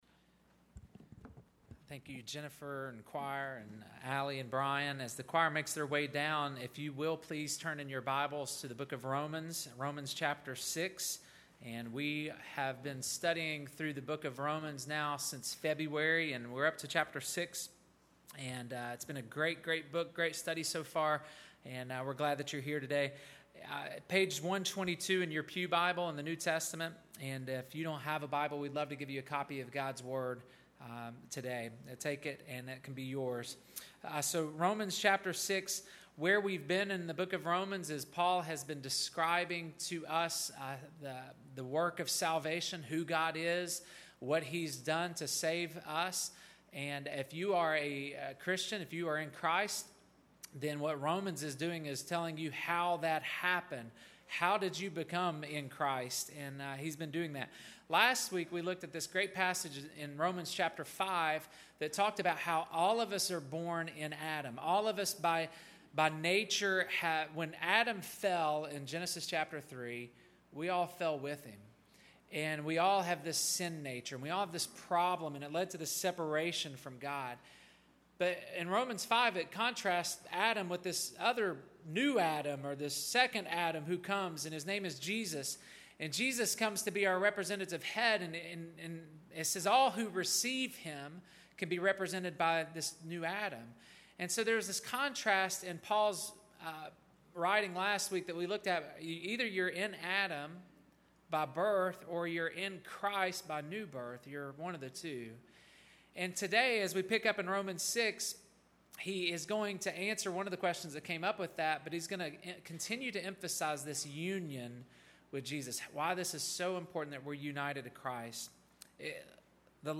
A Matter of Death & Life June 30, 2019 Listen to sermon 1.